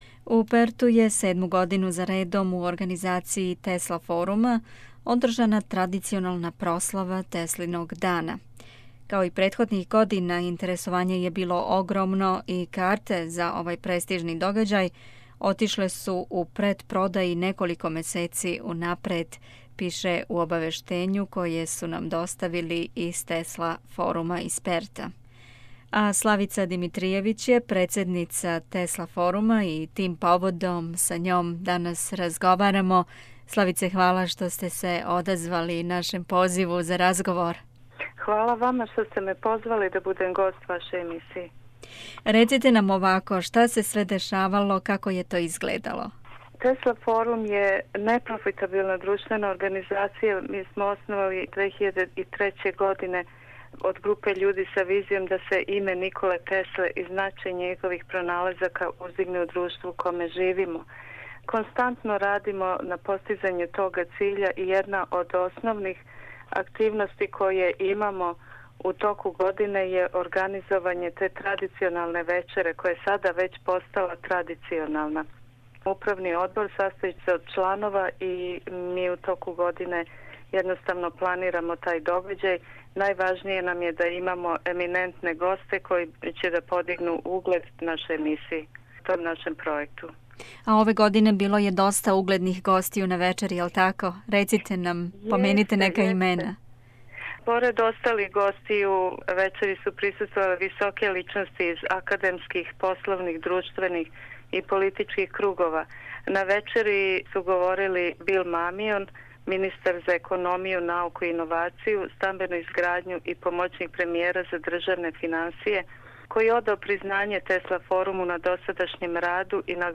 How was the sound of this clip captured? The interview is downloaded from the SBS web site.